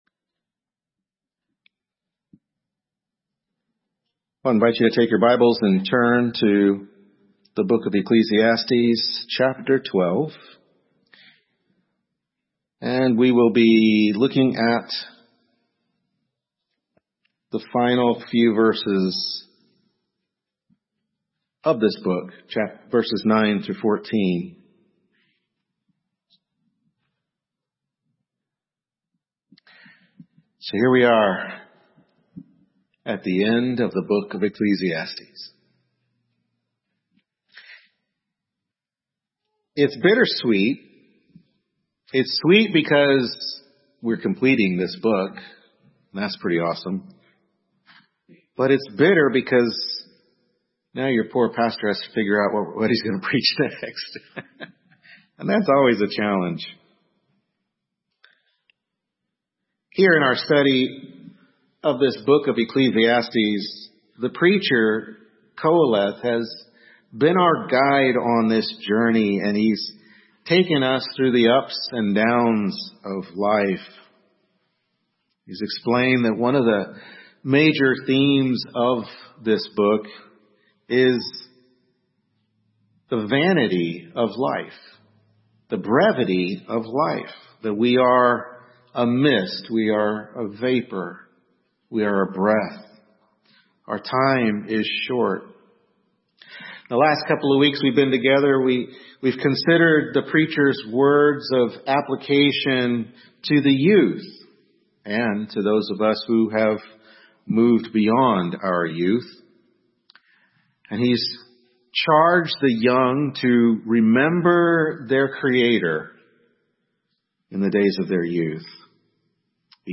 Service Type: Morning Worship Service